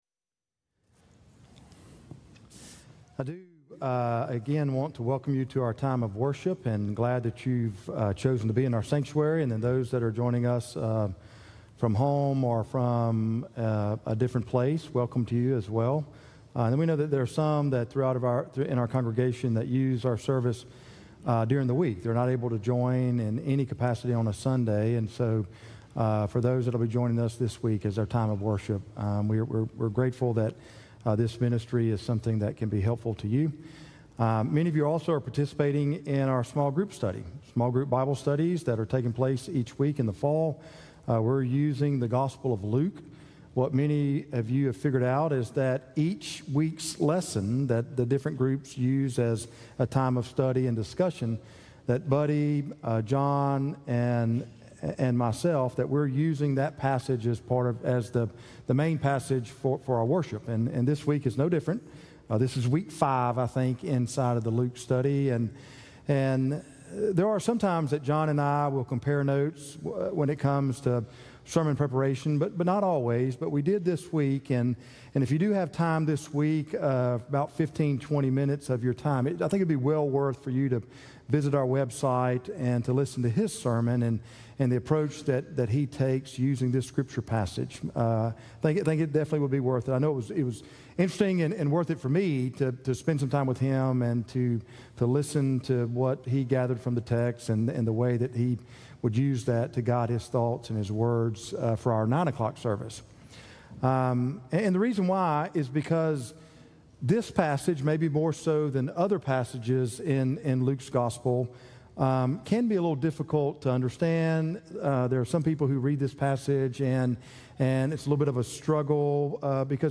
Traditional Worship Service/Luke 8:26-39